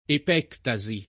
[ ep $ kta @ i ]